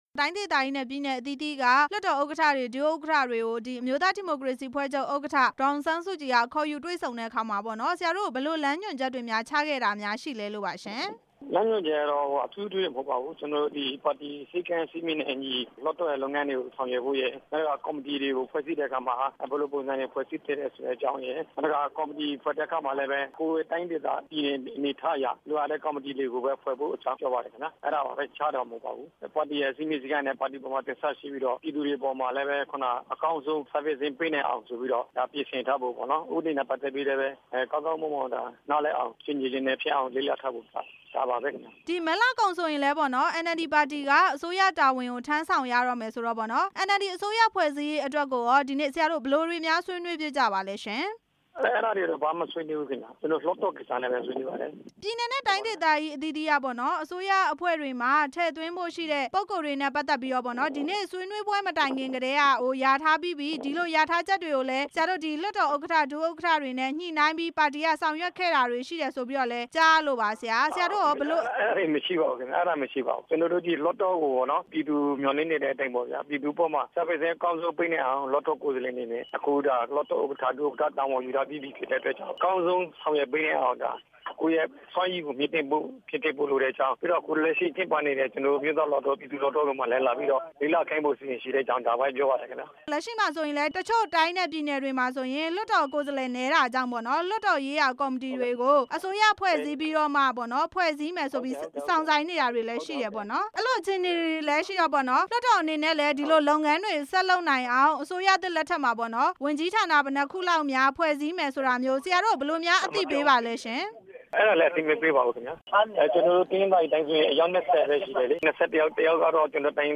ဒီနေ့ တွေ့ဆုံပွဲကို တက်ရောက်ခဲ့တဲ့ တနင်္သာရီတိုင်းဒေသကြီး လွှတ်တော် ဥက္ကဌ ဦးခင်မောင်အေးကို အာအက်ဖ်အေ သတင်းထောက်
ဆက်သွယ် မေးမြန်းထားပါတယ်။